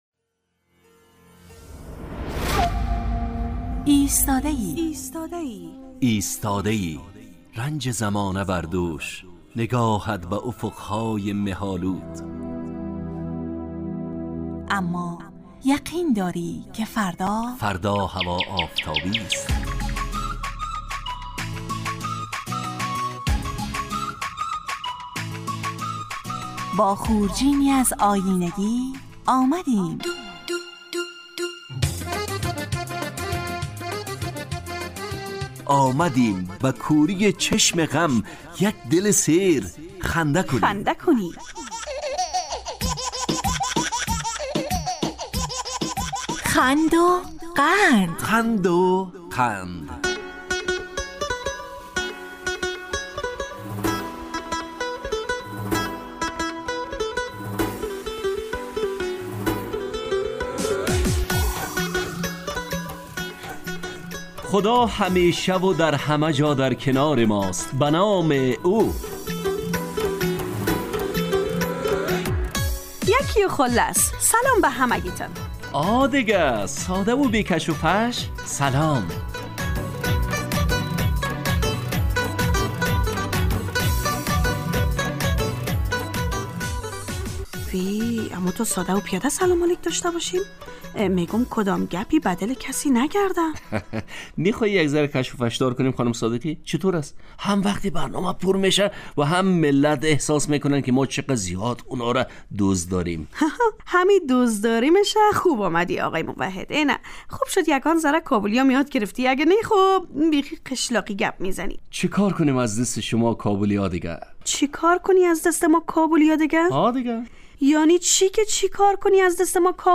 خند وقند برنامه ای طنز در قالب ترکیبی نمایشی است که هرجمعه به مدت 35 دقیقه در ساعت 9:15 به وقت ایران و 10:15 به وقت افغانستان از رادیو دری پخش میگردد.